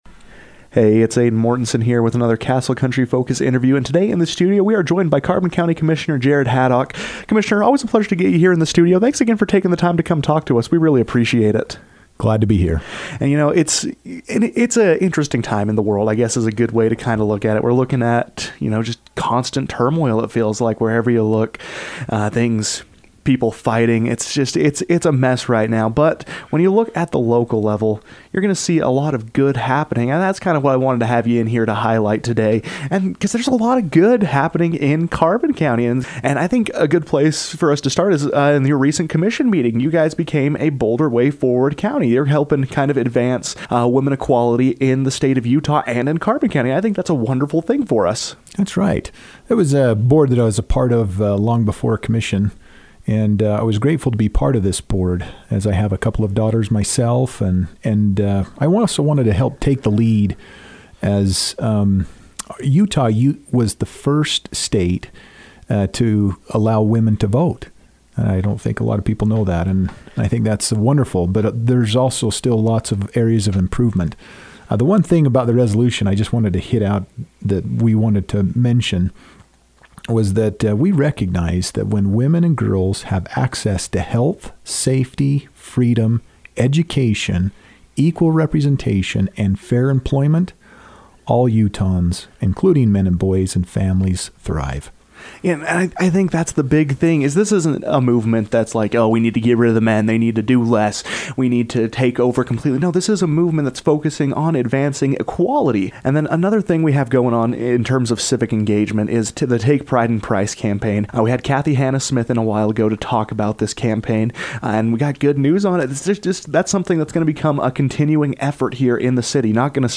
Following the biweekly meeting of the Carbon County Commission, Commissioner Jared Haddock joined the KOAL newsroom to discuss the designation of Carbon County as a 'Bolder Way Forward County', industry arriving in the area and finding civility in politics.